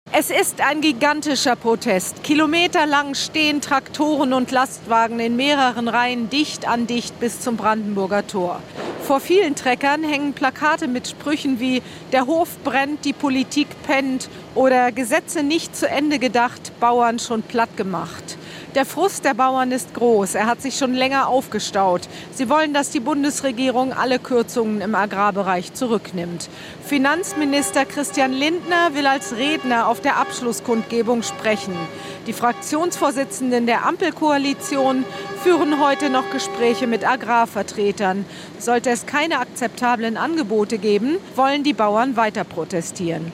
Abschlusskundgebung: Tausende Bauern am Brandenburger Tor in Berlin